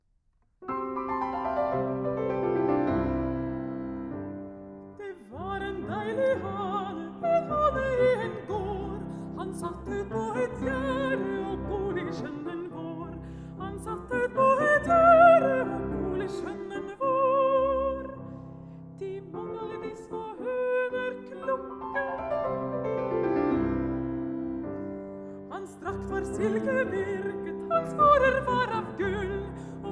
Classical
Жанр: Классика